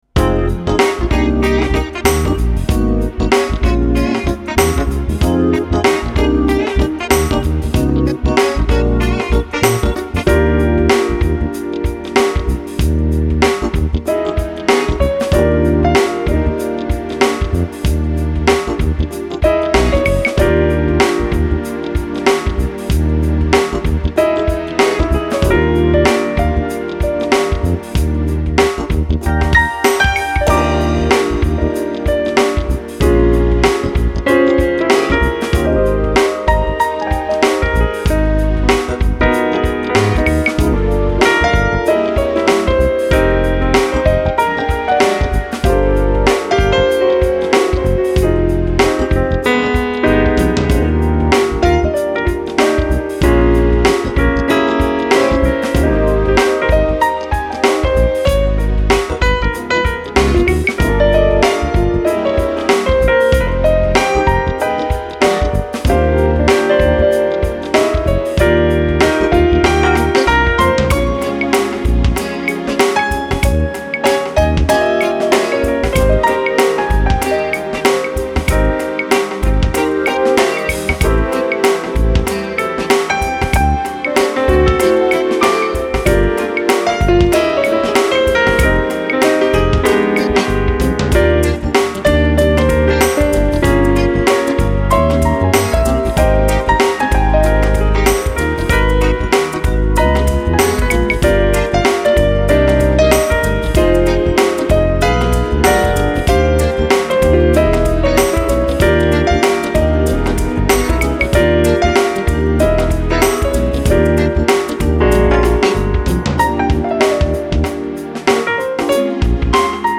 erstklassiger Pianist für Ihre Veranstaltung